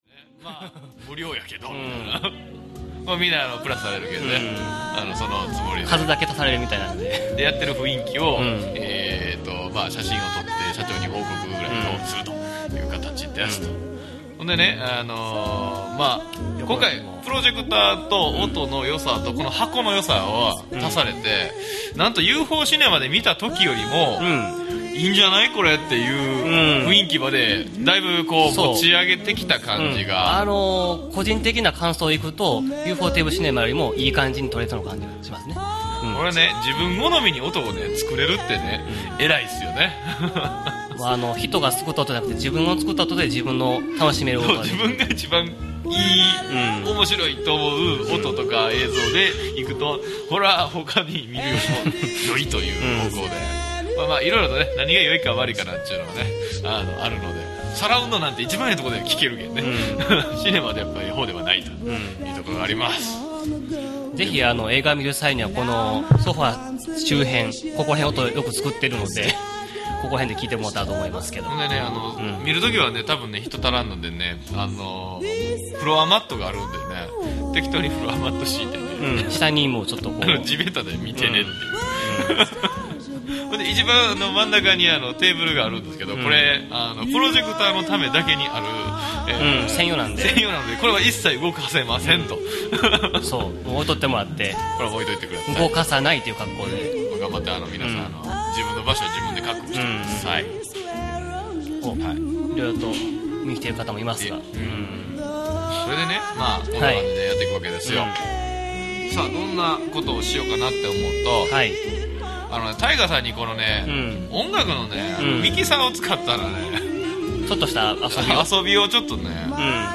（公開収録）   計画中 [1] 5:00